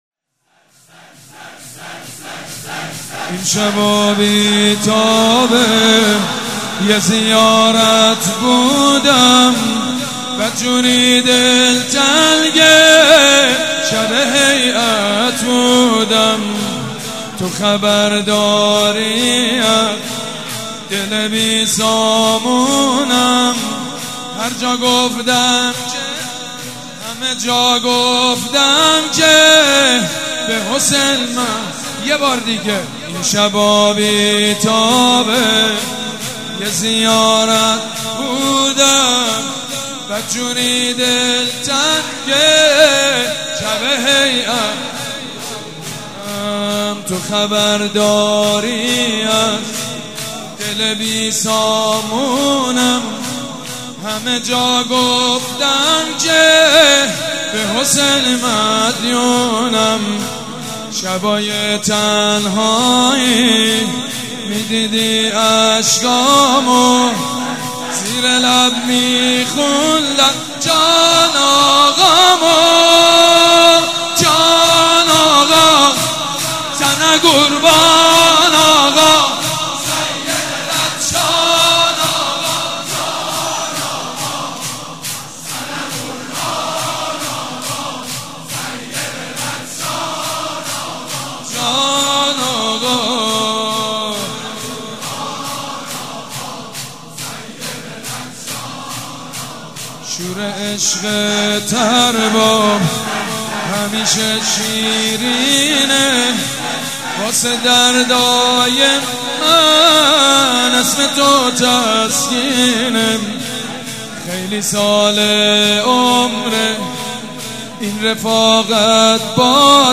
شب پنجم فاطميه دوم١٣٩٤
شور
مداح
حاج سید مجید بنی فاطمه
مراسم عزاداری شب شهادت حضرت زهرا (س)
shoor.mp3